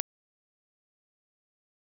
delta.wav